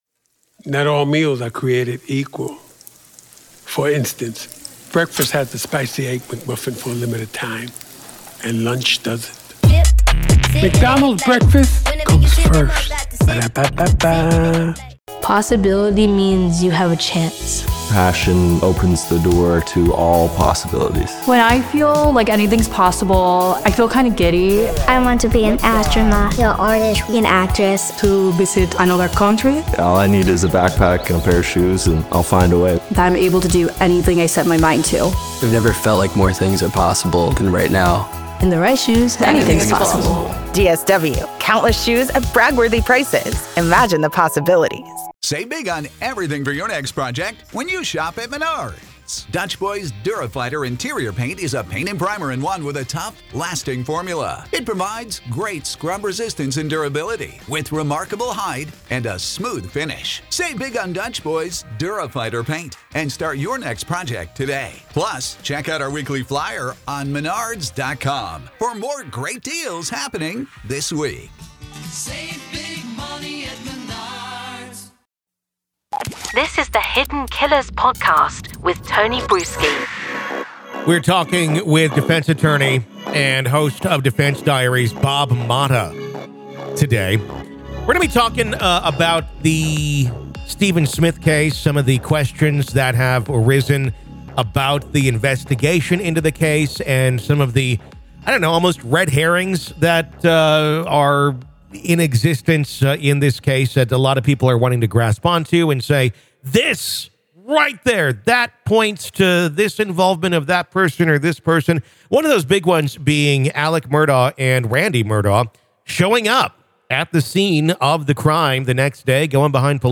The interview sheds light on the case and gives viewers an understanding of the legal and ethical responsibilities of witnesses in criminal investigations.